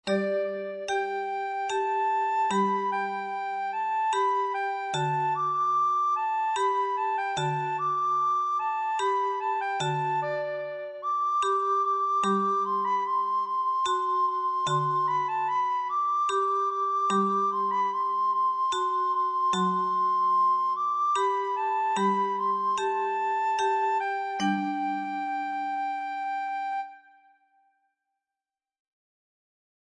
Musica_acuatica_-_Orquestracion.mp3